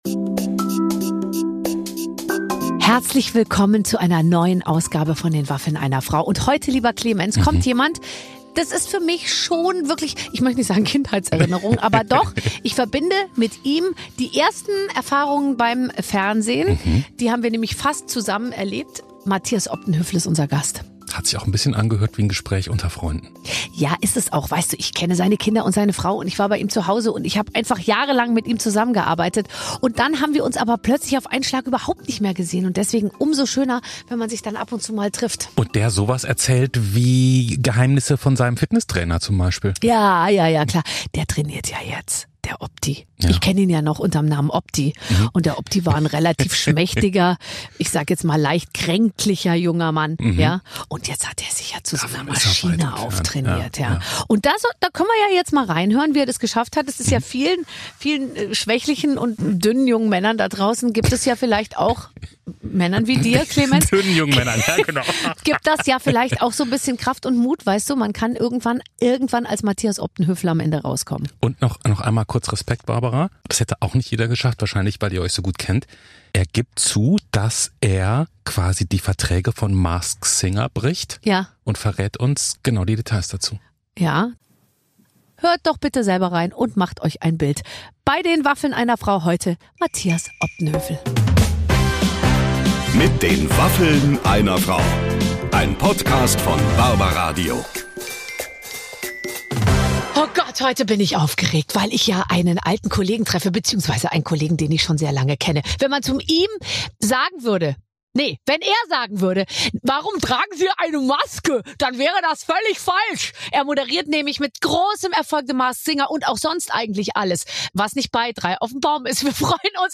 Matthias Opdenhövel ist zu Gast bei Barbara Schöneberger und die beiden kennen sich schon viele Jahre.